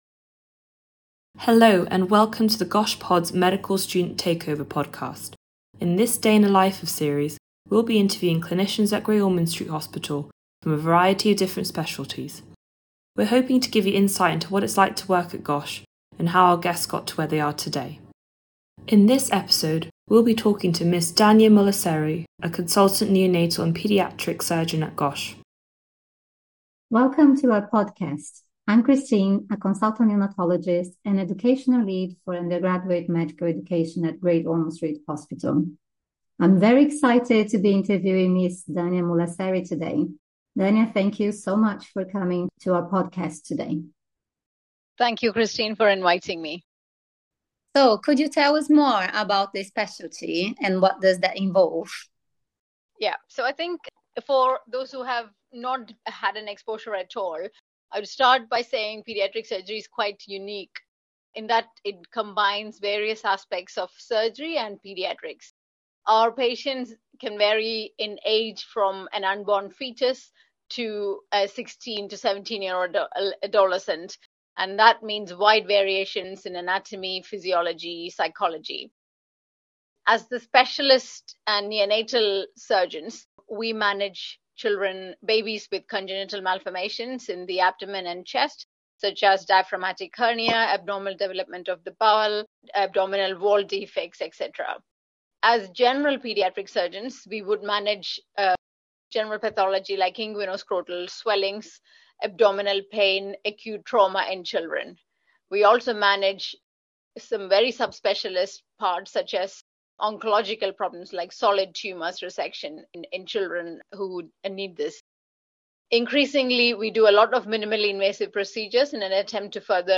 In each episode they will be focussing on a different specialty within paediatrics, and interviewing a consultant in that specialty about their daily routine, their personal career pathway, the highlights of their job, as well as their advice to medical students and junior doctors interested in a future career in paedia